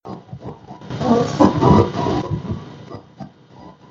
Â I used the induction loop receiver to listen in to the sound of my computer.